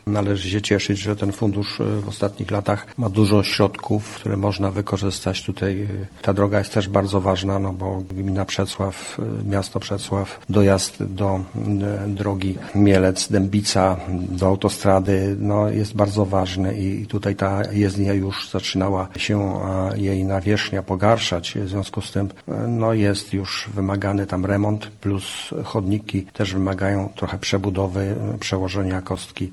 Mówi starosta mielecki Stanisław Lonczak.